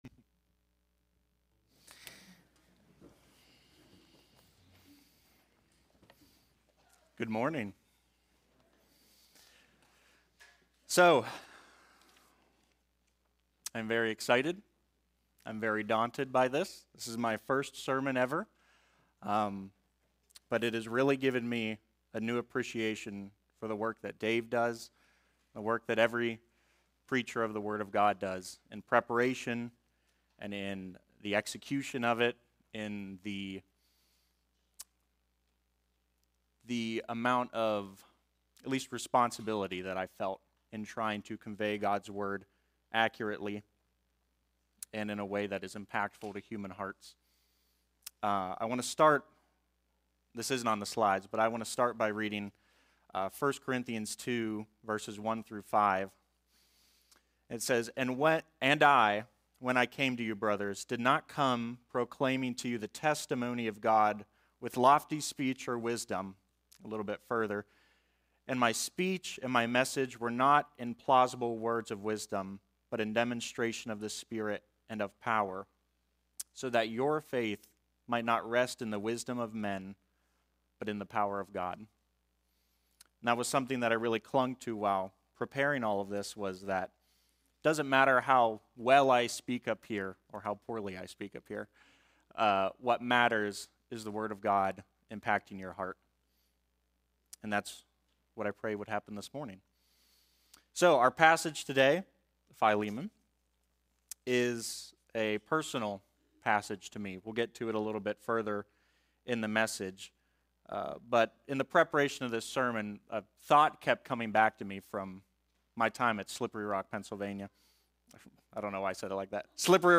Various Sermons
Guest Speaker